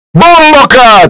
Memes
Loud Bomboclat